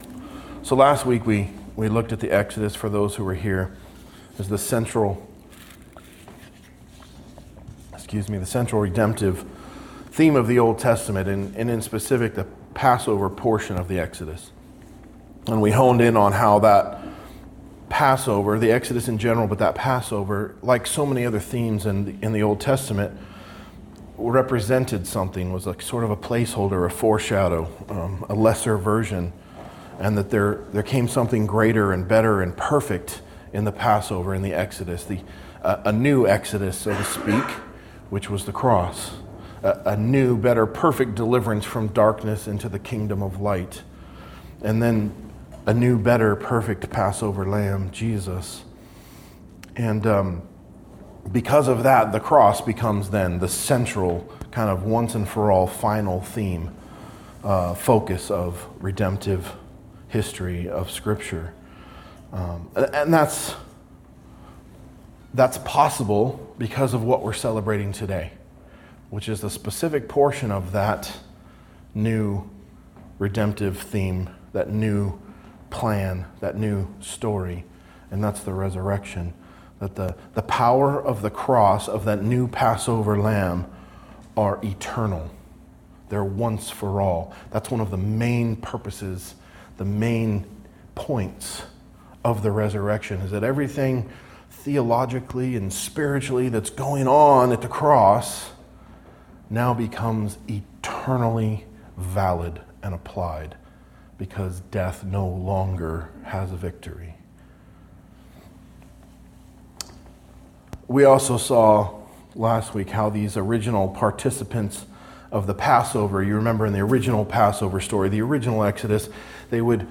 A message from the series "Matthew." Matthew 19:1-12